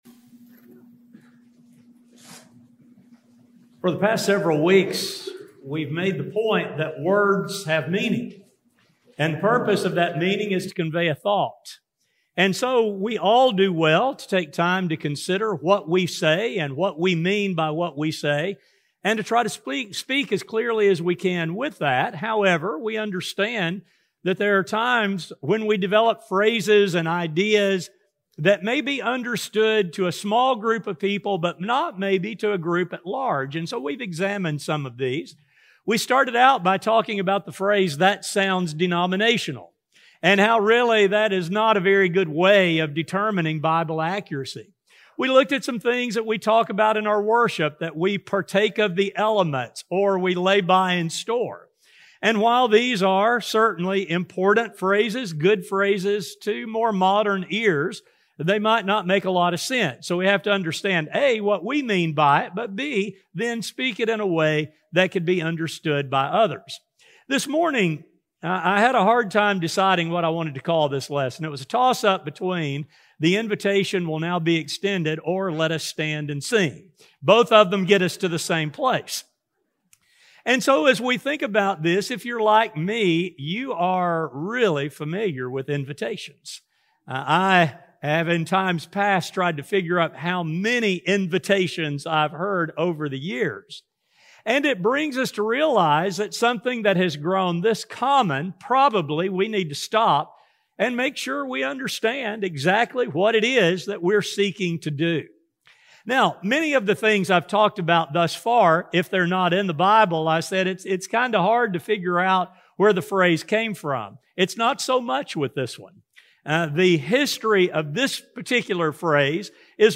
This sermon contains a discussion of this tradition.